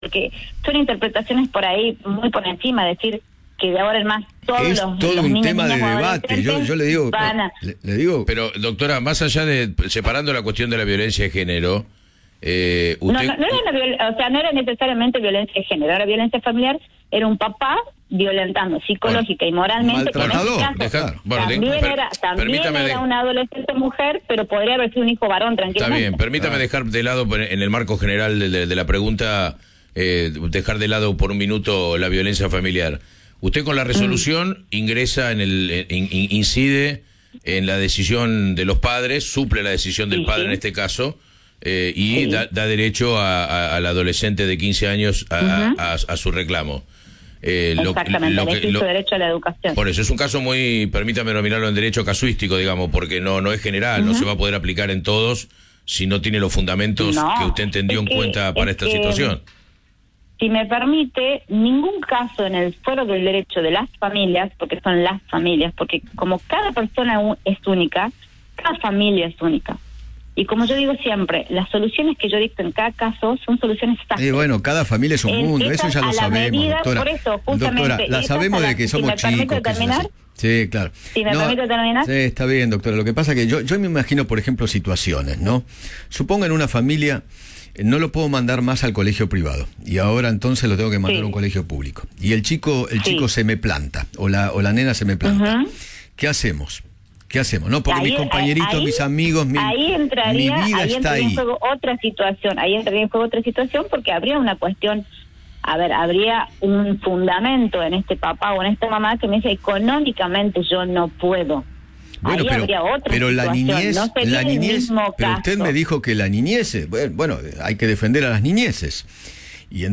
Eduardo Feinmann conversó con la jueza de familia de Catamarca, Olga Amigot Solohaga, sobre su fallo a favor de una adolescente que no quería que sus padres la cambien de colegio.